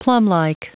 Prononciation du mot plumlike en anglais (fichier audio)